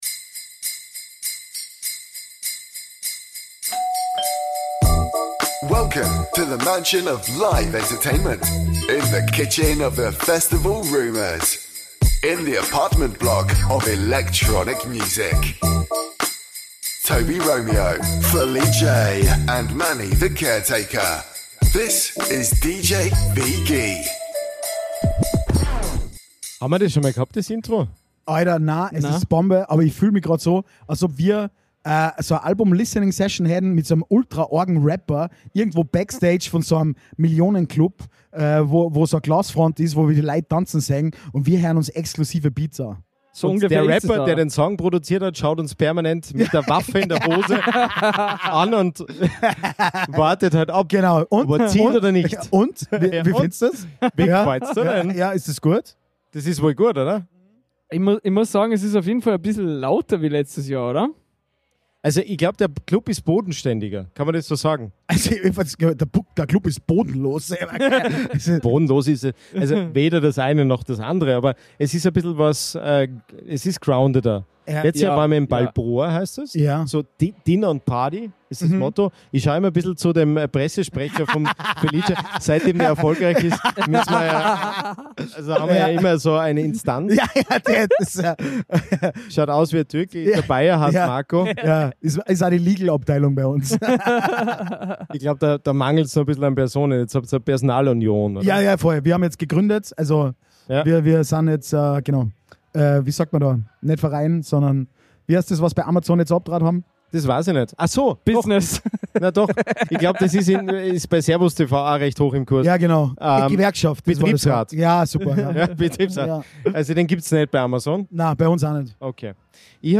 Beschreibung vor 1 Jahr Jahresgespräche auf einem tieferen Niveau, nämlich in den Katakomben des Half Moon. Viel Schönes gab's zu besprechen, aber auch Terroralarm und die Glue Boys wurden verhaftet.